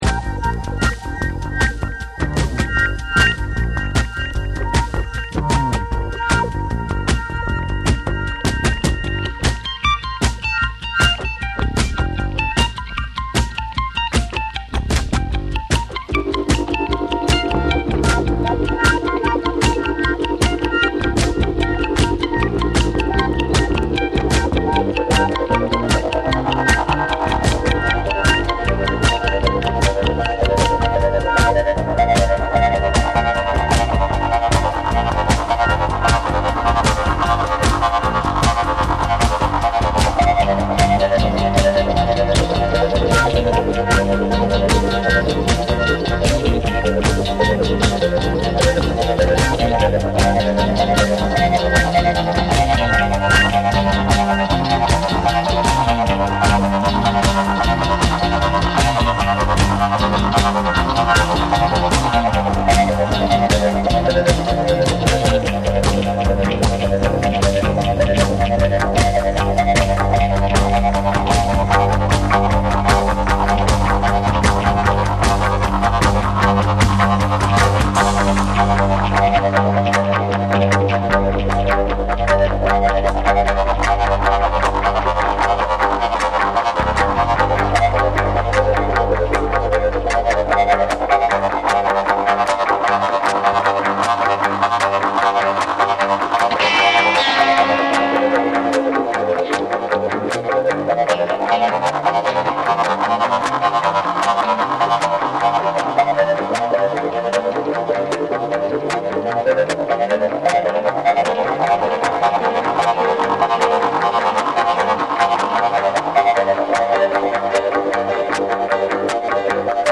TECHNO & HOUSE / NEW WAVE & ROCK